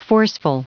Prononciation du mot forceful en anglais (fichier audio)
Prononciation du mot : forceful